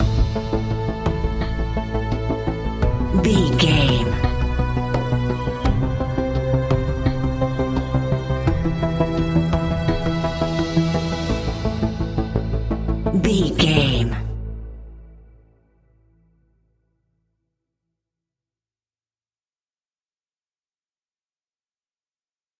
In-crescendo
Thriller
Aeolian/Minor
ominous
dark
haunting
eerie
strings
synthesiser
drums
horror music